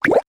Звуки лопающихся пузырьков
Короткий хлопок лопающегося пузыря